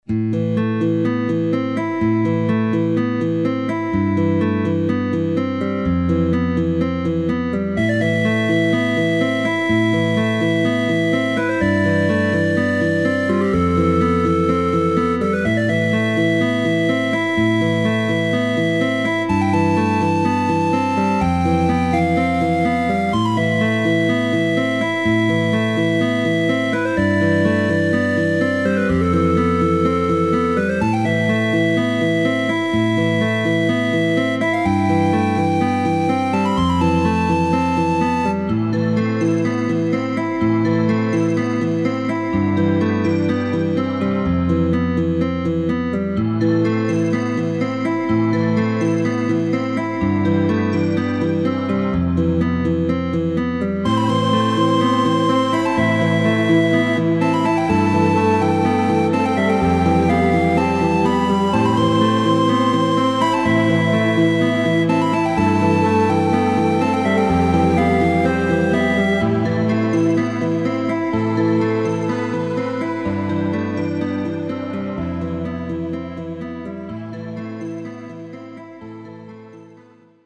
Инструментальные композиции: